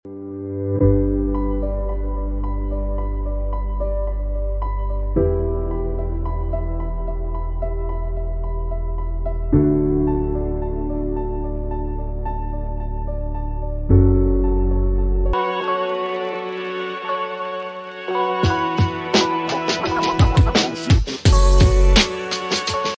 Relaxing Enchanting Atmosphere of Autumn Sunset Ambiance
Let the warm hues and calming ambiance help you unwind and relax, as you find solace in the beauty of the season.